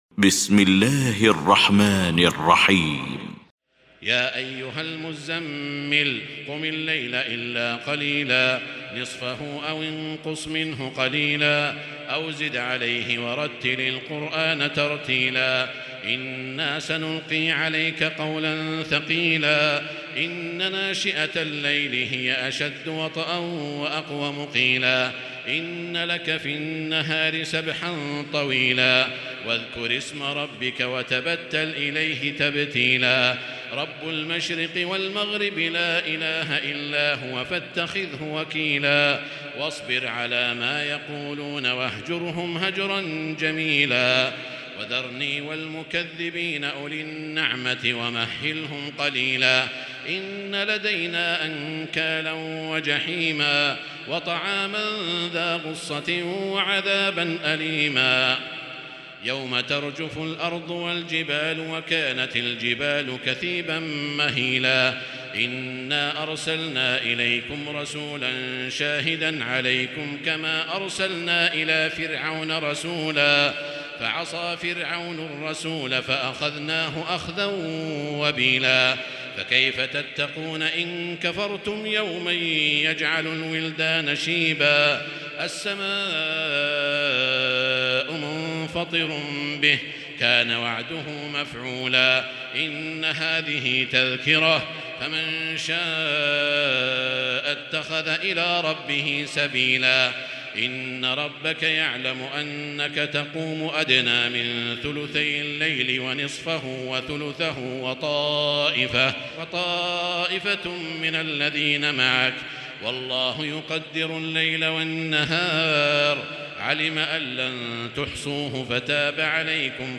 المكان: المسجد الحرام الشيخ: سعود الشريم سعود الشريم المزمل